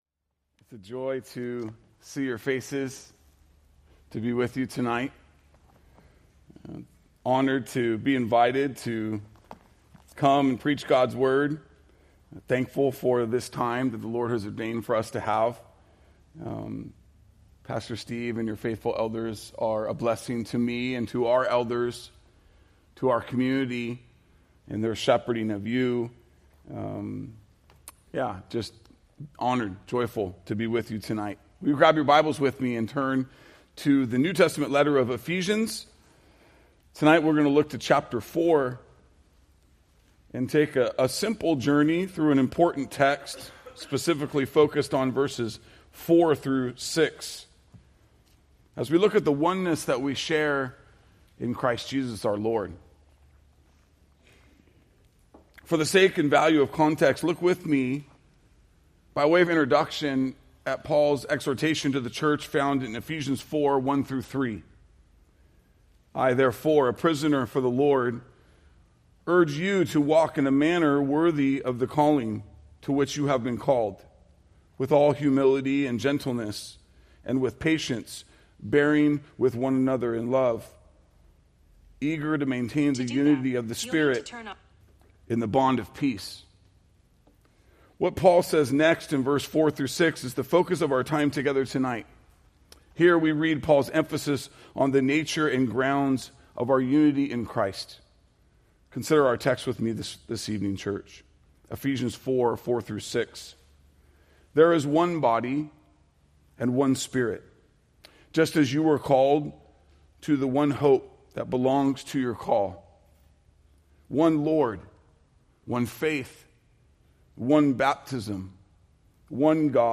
Preached August 11, 2024 from Ephesians 4:4-6